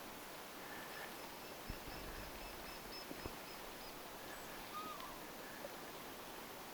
pikkutikka
pikkutikka_tietaakseni.mp3